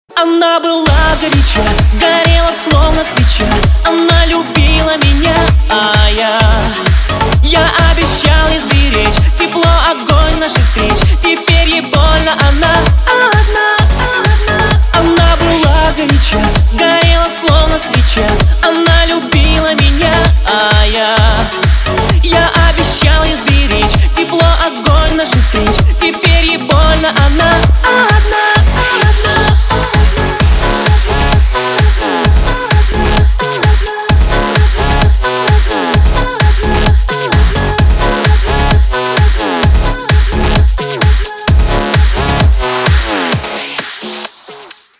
• Качество: 192, Stereo
громкие
грустные
попса
Eurodance